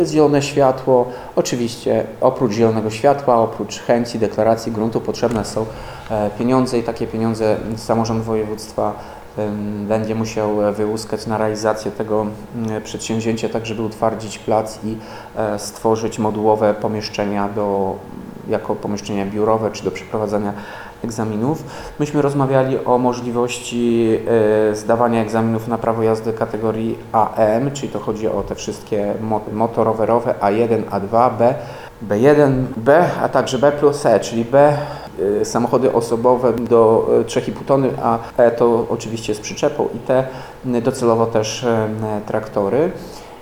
Mówił Tomasz Andrukiewicz, prezydent Ełku.